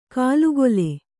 ♪ kālugole